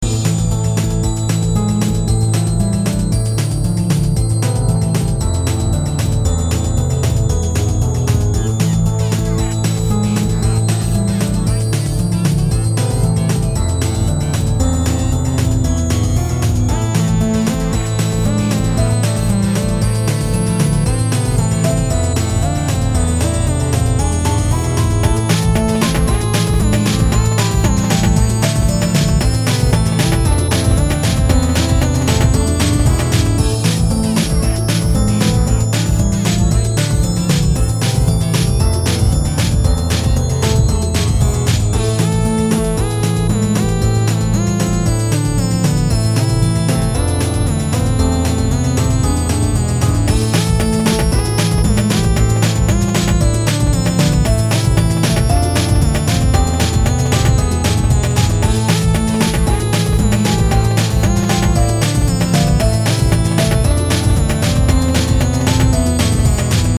Key Instruments: Piano, Synth, Synth Voice